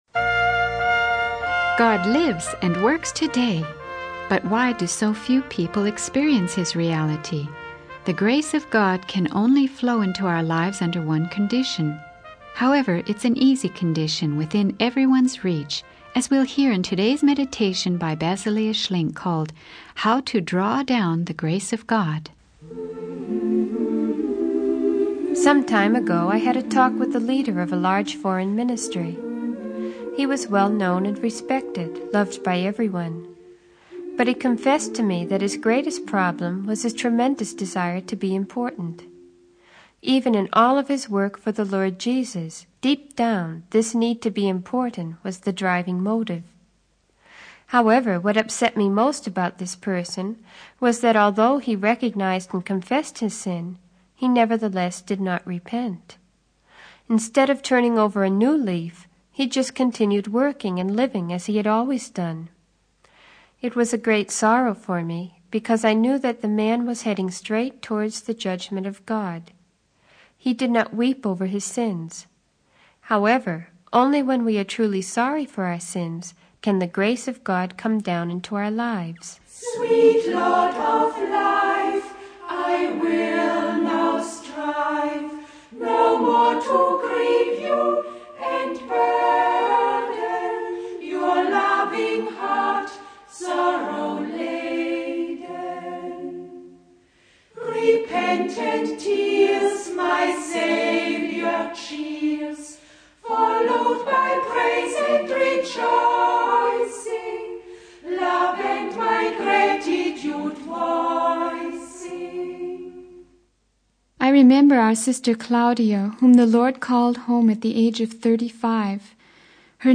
The sermon teaches that the condition for the grace of God to flow into our lives is to be truly sorry for our sins and repent.